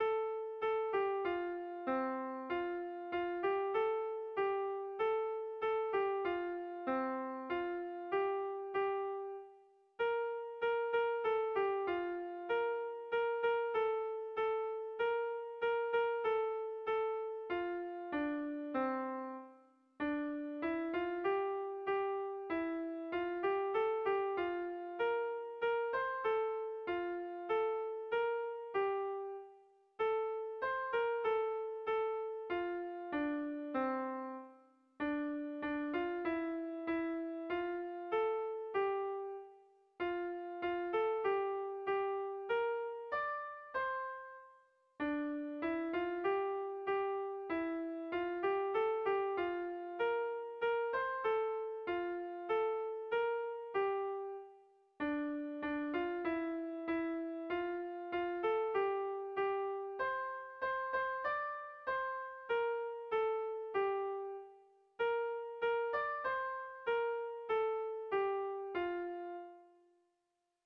Kontakizunezkoa
Hamabikoa, handiaren moldekoa, 7 puntuz (hg) / Zazpi puntukoa, handiaren moldekoa (ip)
ABDE...